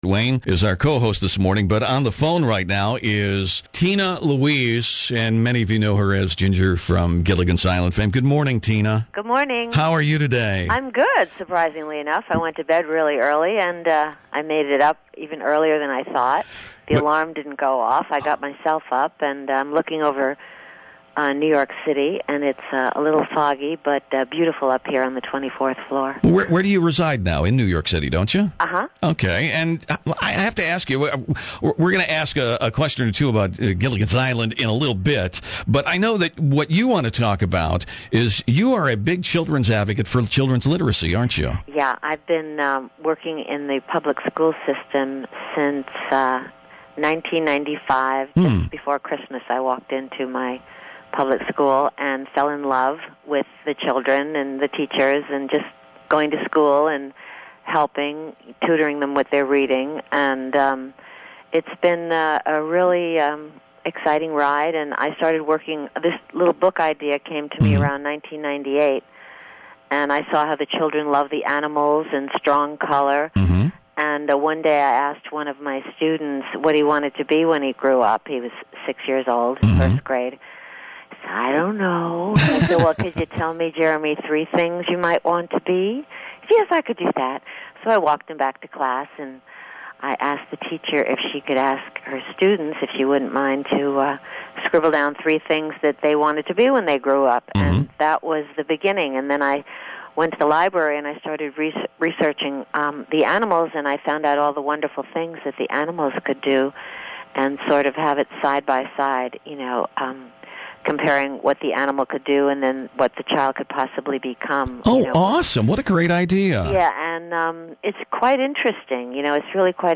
A long-lost interview from 2010, when I got to spend quality time on the phone with Tina Louise!